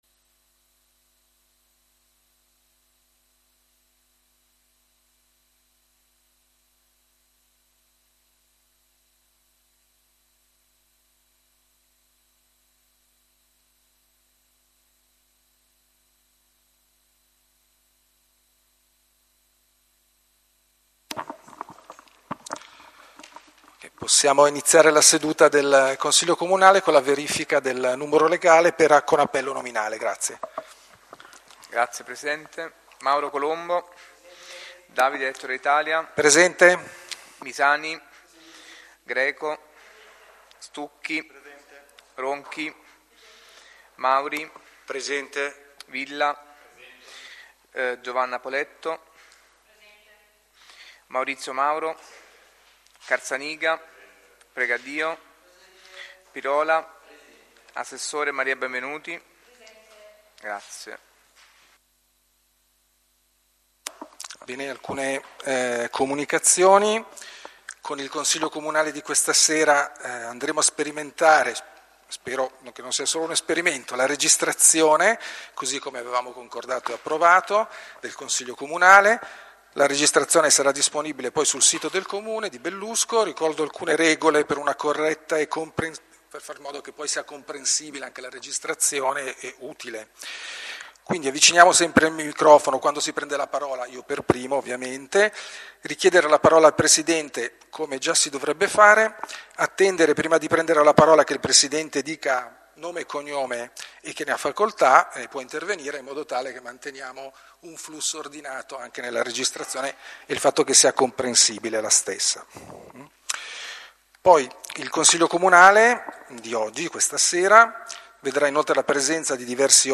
Registrazioni audio delle sedute del consiglio comunale del 2025